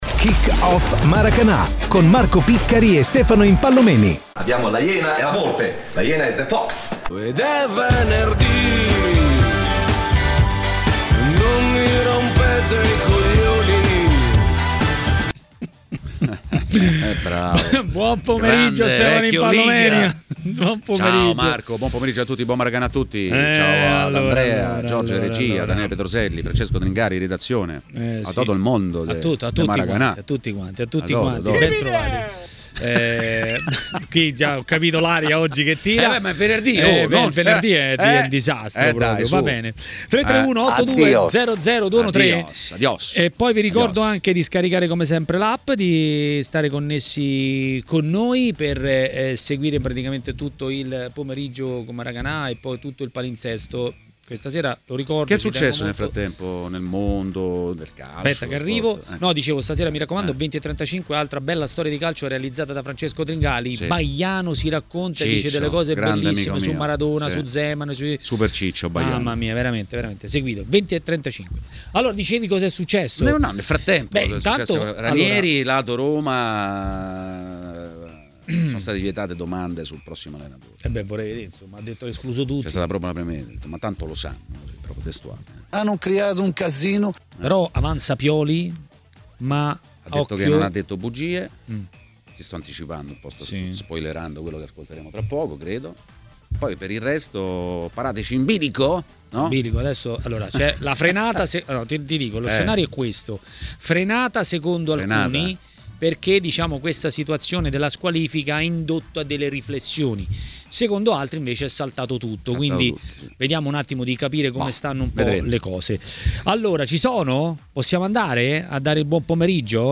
A parlare dei temi di giornata a TMW Radio, durante Maracanà, è stato il giornalista ed ex calciatore Stefano Impallomeni.